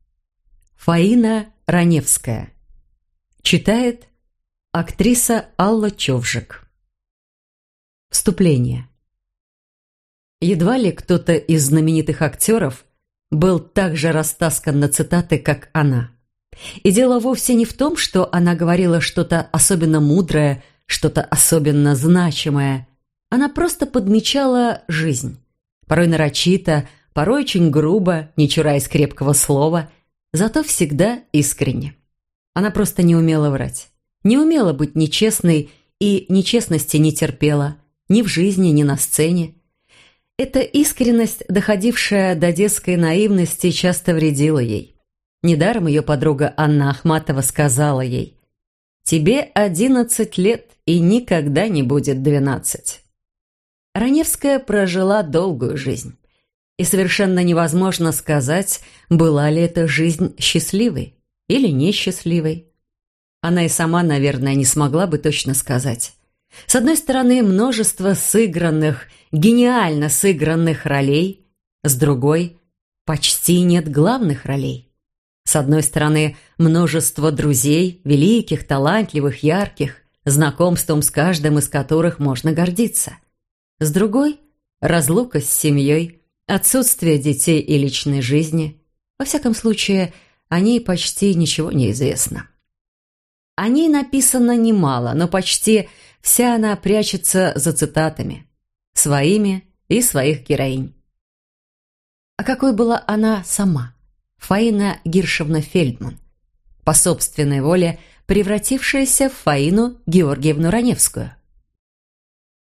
Аудиокнига Фаина Раневская | Библиотека аудиокниг